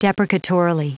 Prononciation du mot deprecatorily en anglais (fichier audio)
Prononciation du mot : deprecatorily